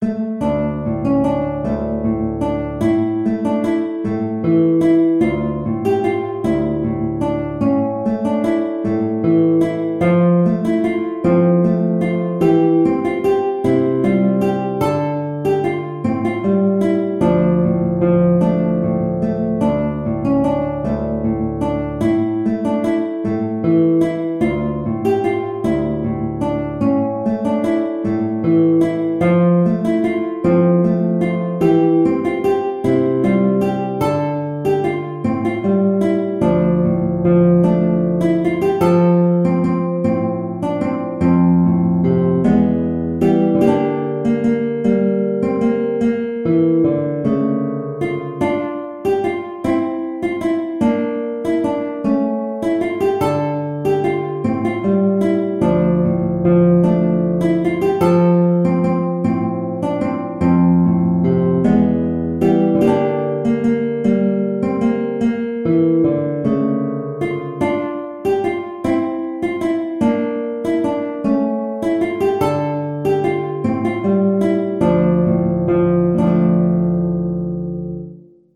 Traditional Trad. Lancaster Lasses Guitar version
6/8 (View more 6/8 Music)
A minor (Sounding Pitch) (View more A minor Music for Guitar )
Instrument:
Traditional (View more Traditional Guitar Music)
Lancaster_Lasses_Gtr.mp3